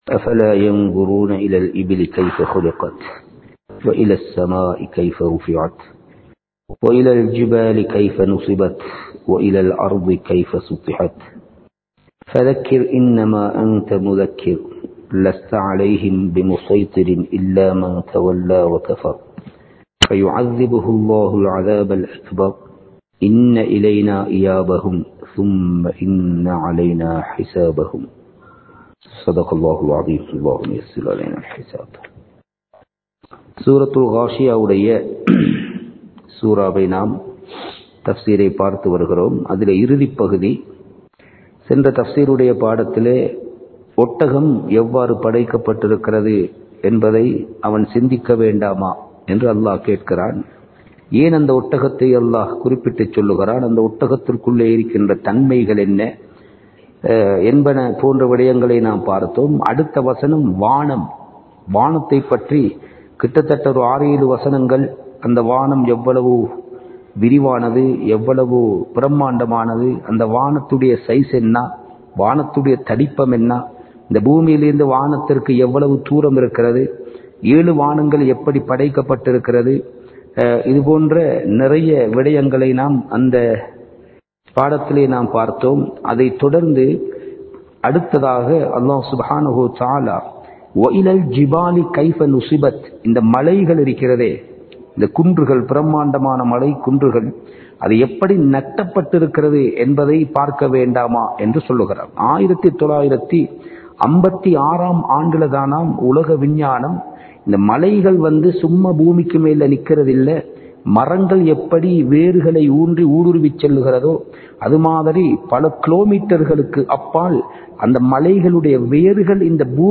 தப்ஸீர் வகுப்பு 59 | Audio Bayans | All Ceylon Muslim Youth Community | Addalaichenai
Kandy, Kattukela Jumua Masjith